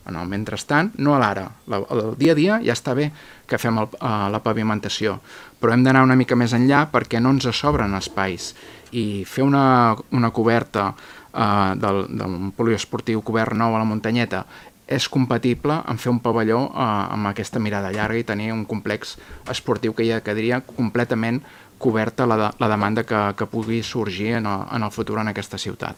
Durant la sessió plenària, el grup municipal d’ERC va insistir en la seva demanda per ubicar una altra pista coberta al Tomàs Claramunt mentre no es resol el projecte de segon pavelló poliesportiu a Calella.
Xavier Ponsdomènech (ERC):